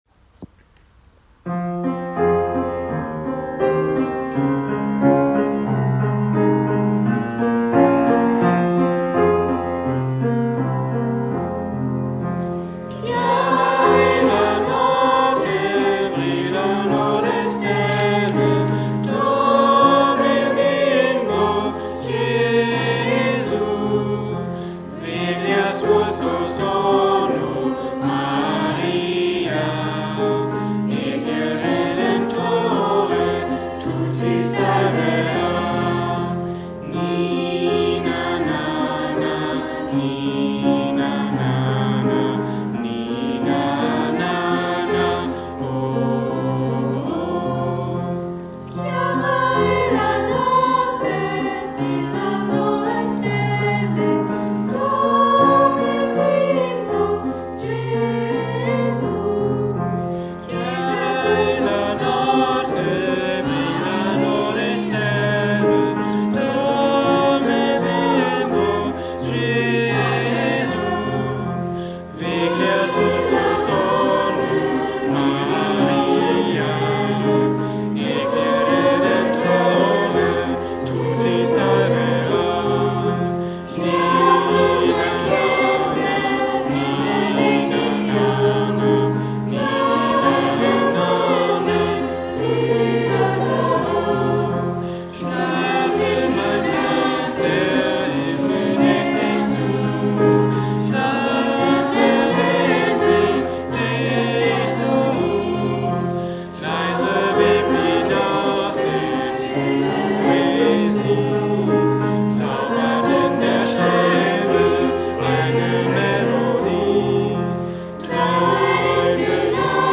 Heute gibt es nochmal einen Beitrag von unserem Chor die „Raising Voices“ für euch.Wir wünschen euch viel Spaß mit „Ciara“